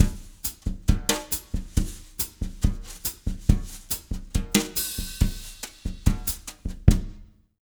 140BOSSA07-R.wav